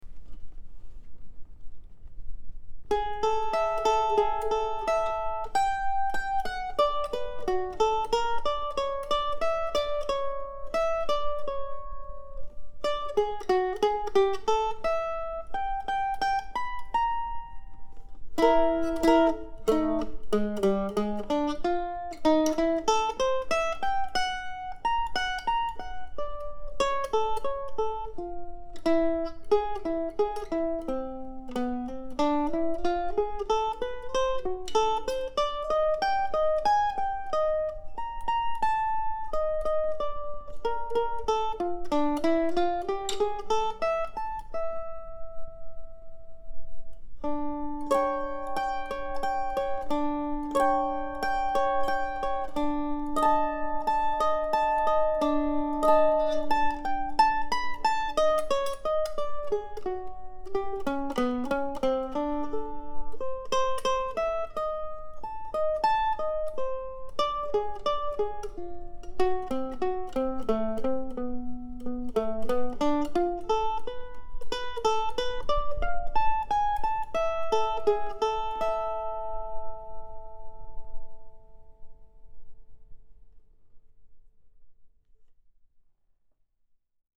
I know these pieces sound pretty random but I actually spend some time making choices about pitches and durations.